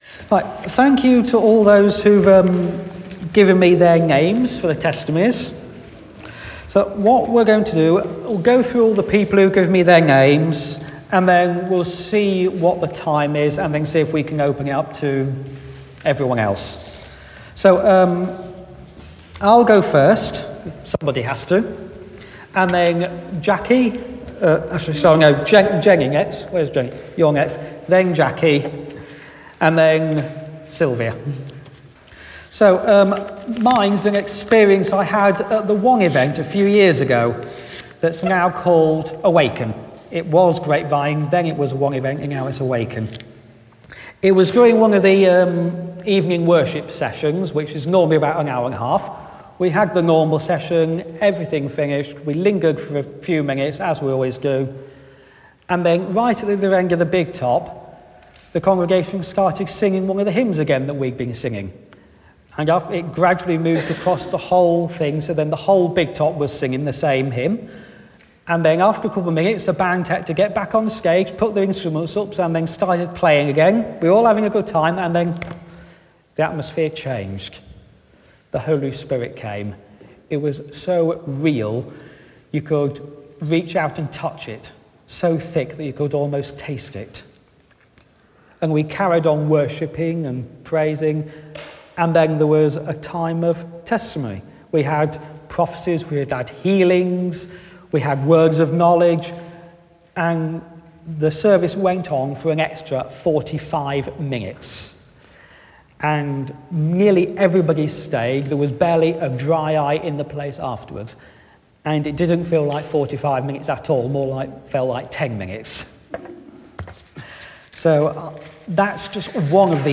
Testimonies
7 brief testimonies from some members of the congregation about God's working in their life: